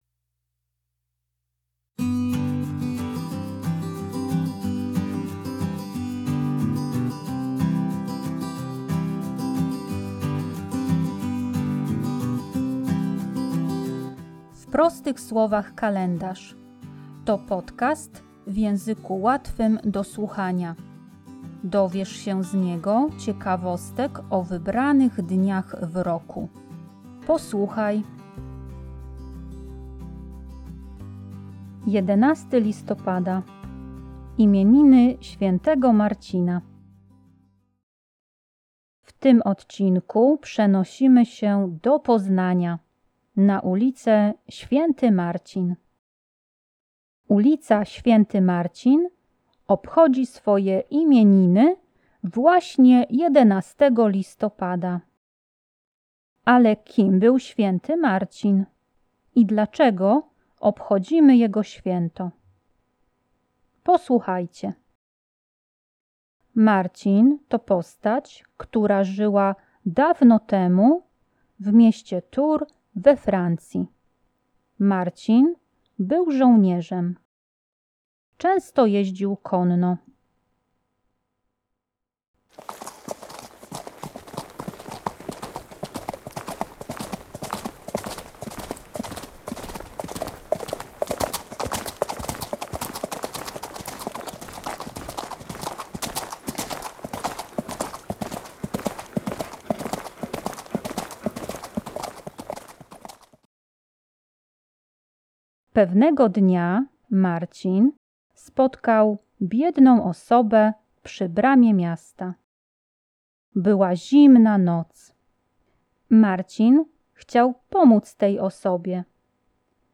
W podcaście usłyszysz stukot kopyt konia.